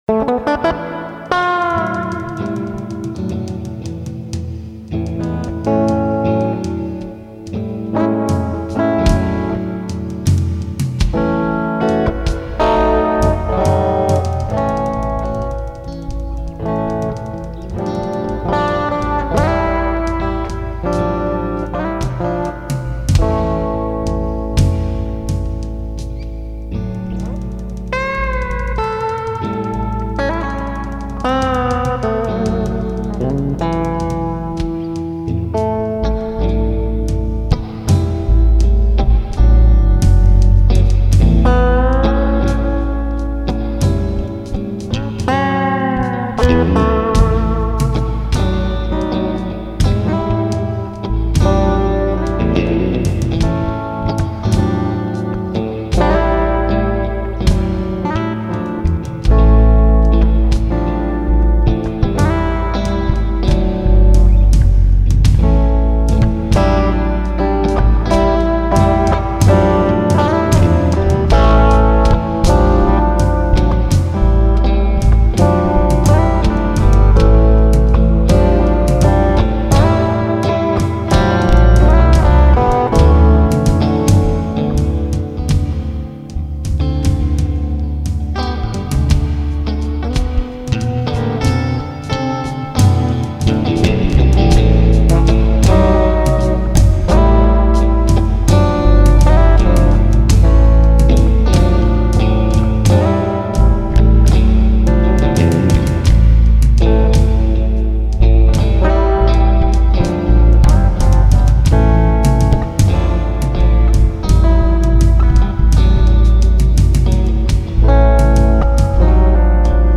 Desert Studios, Queen Creek, AZ